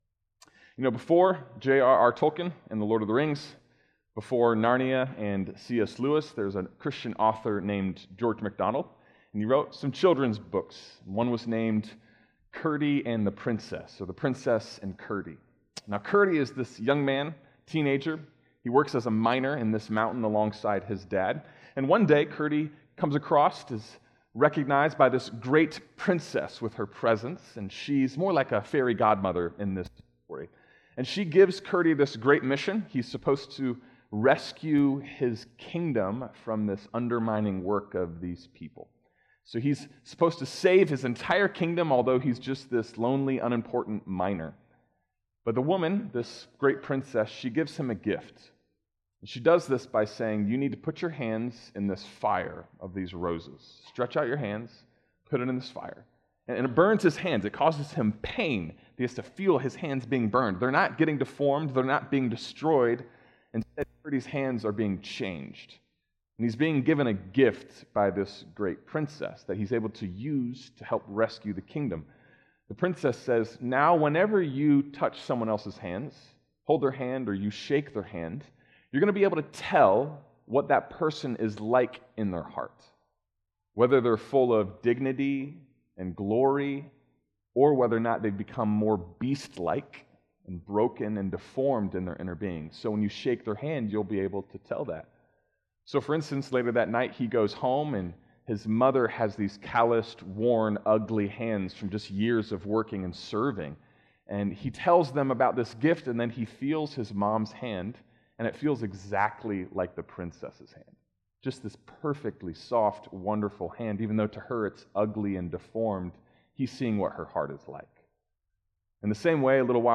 Sermons King's Cross Lyons Podcast - Sin As Addiction/Enslaved | Free Listening on Podbean App